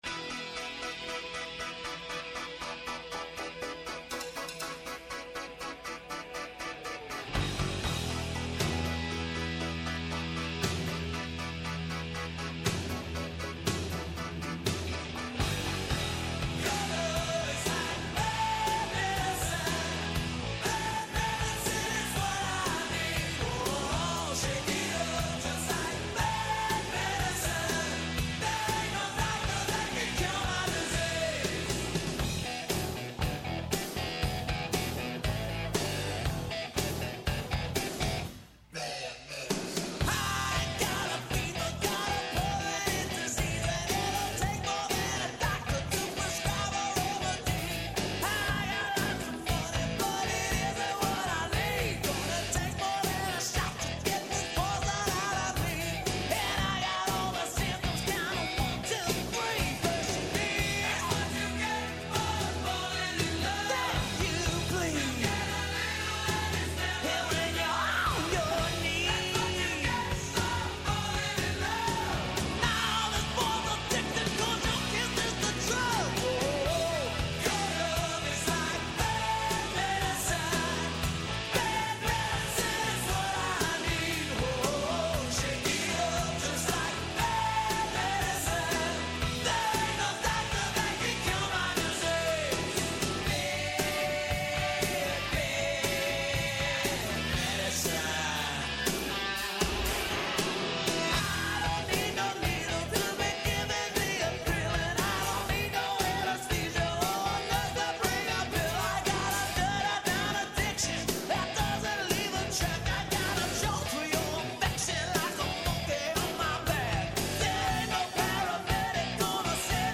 Άνθρωποι της επιστήμης, της ακαδημαϊκής κοινότητας, πολιτικοί, ευρωβουλευτές, εκπρόσωποι Μη Κυβερνητικών Οργανώσεων και της Κοινωνίας των Πολιτών, συζητούν για όλα τα τρέχοντα και διηνεκή ζητήματα που απασχολούν τη ζωή όλων μας, από την Ελλάδα και την Ευρώπη μέχρι την άκρη του κόσμου.